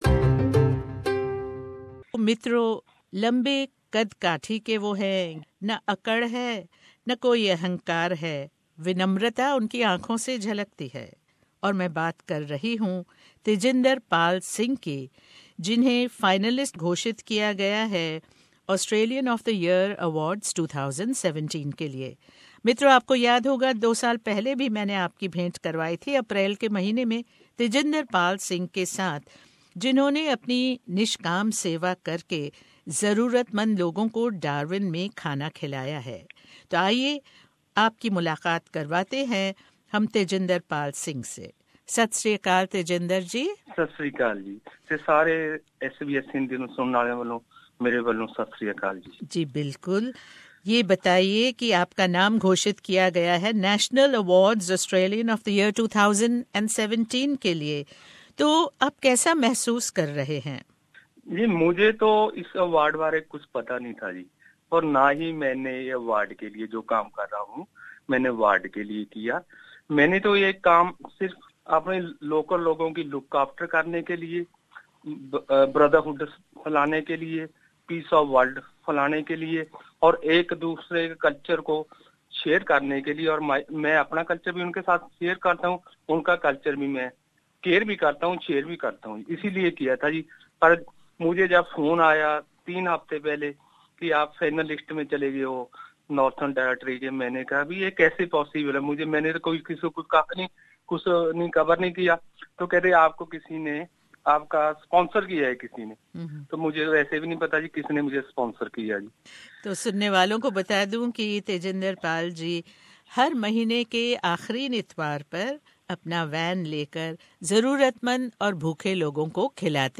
भेंटवार्ता।